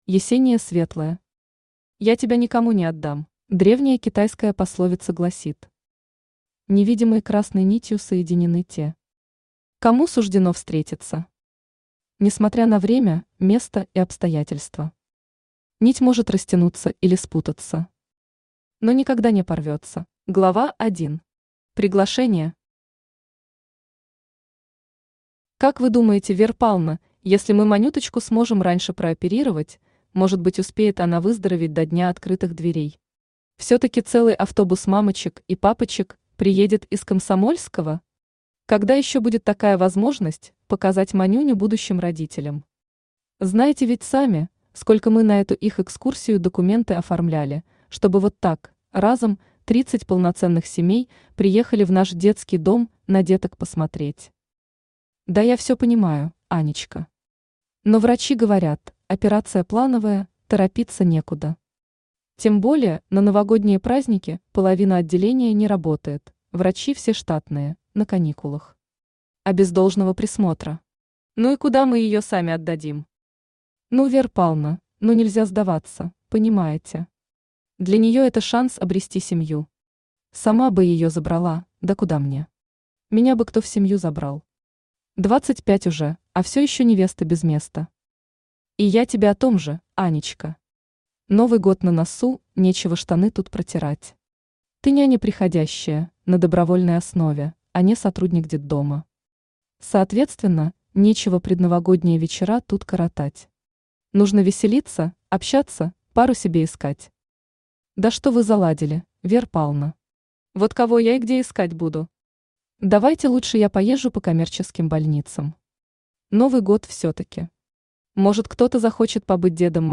Аудиокнига Я тебя никому не отдам…
Автор Есения Светлая Читает аудиокнигу Авточтец ЛитРес.